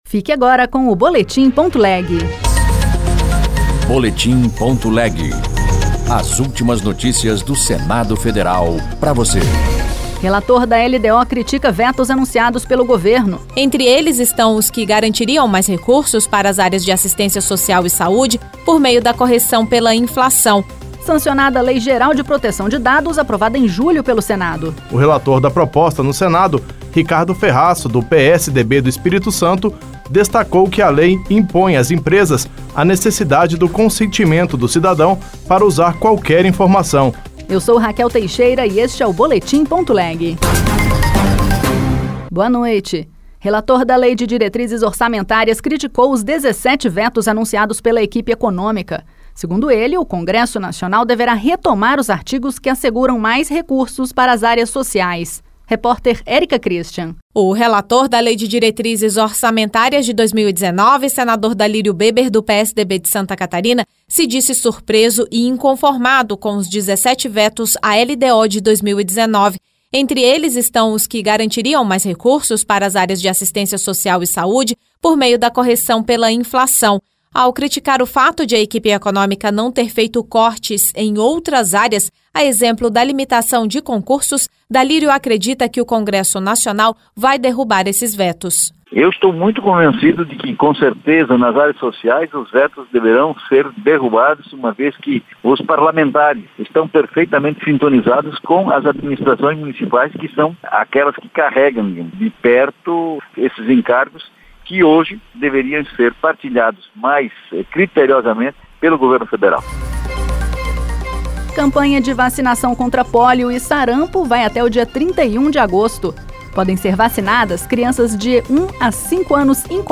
Notícias do Senado Federal em duas edições diárias